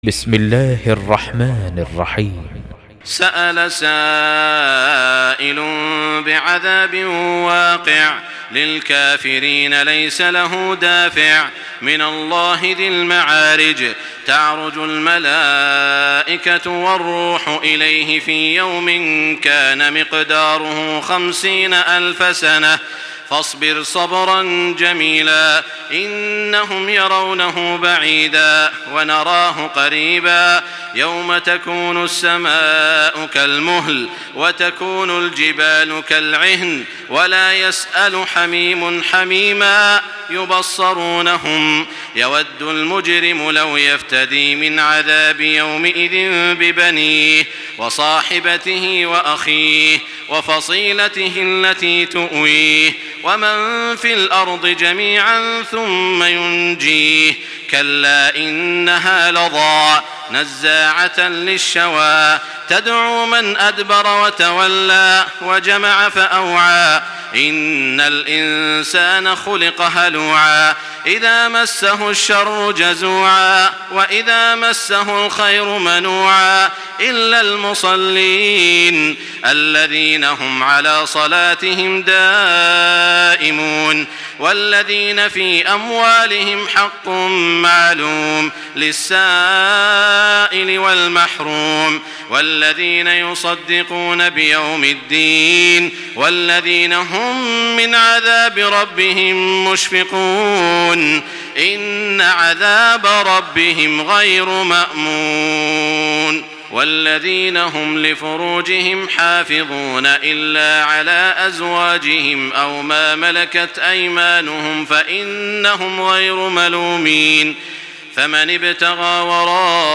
Surah المعارج MP3 by تراويح الحرم المكي 1425 in حفص عن عاصم narration.
مرتل